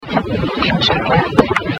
EVP #2